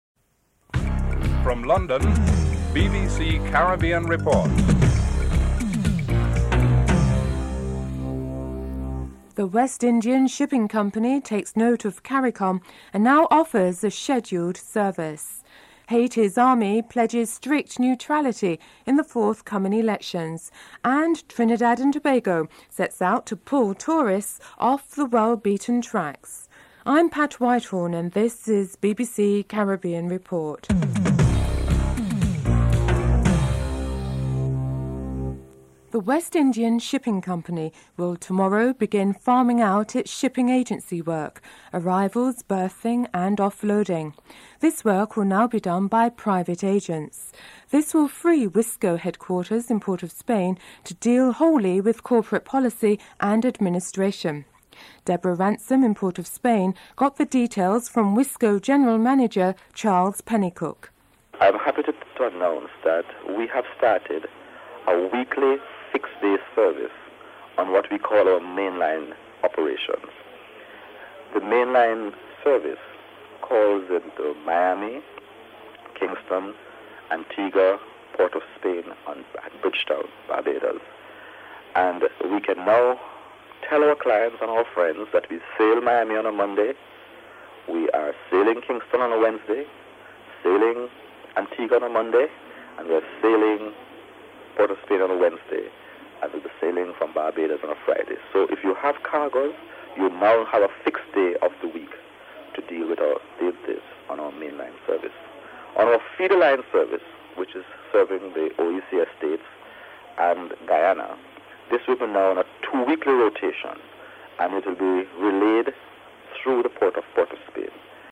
1. Headline (00:00-00:35)